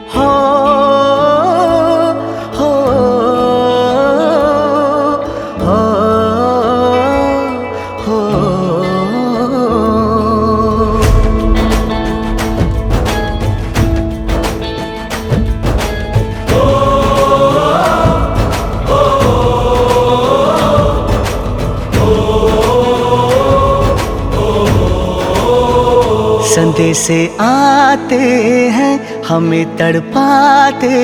emotional patriotic ringtone